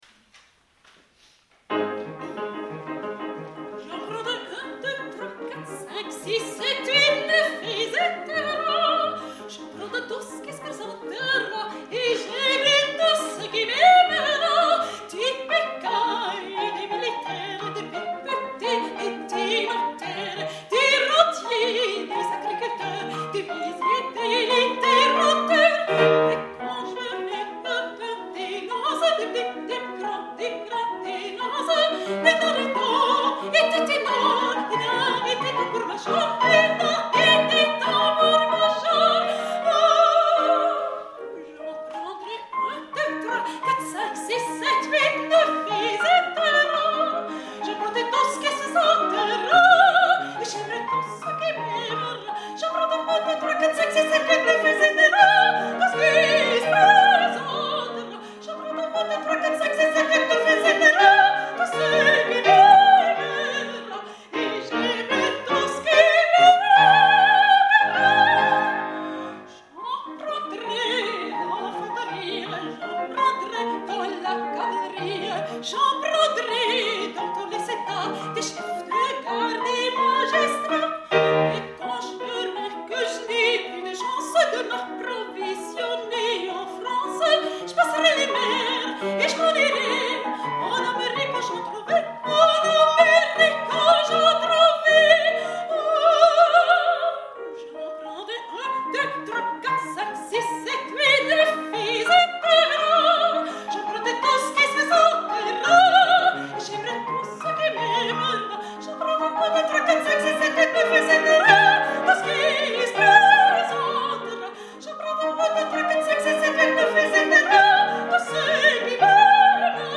classic pro bono    BETRUG: Eine kleine Operngala
Arien und Duette von Mozart bis Offenbach
Sopran
Bariton
Klavier